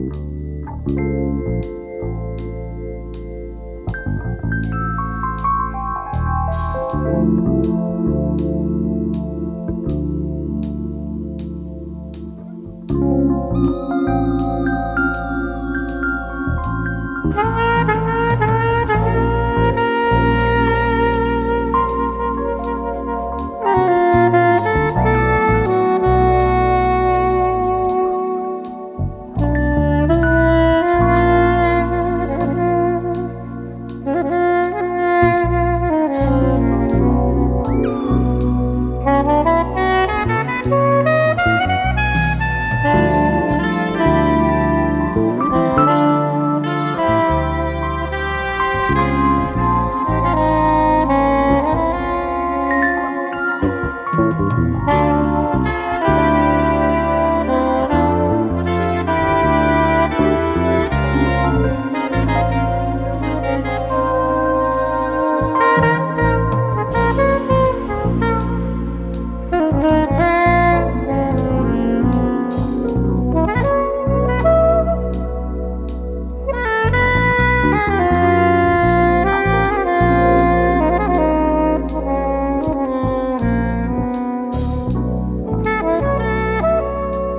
Disco Soul Funk